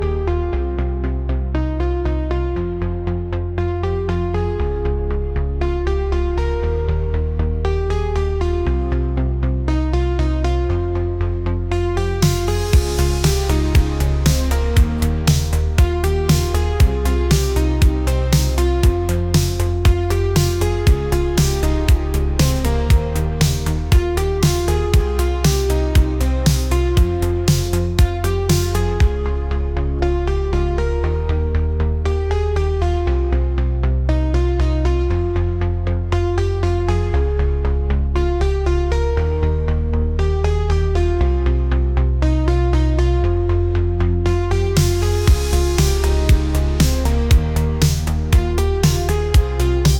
pop | retro | synth pop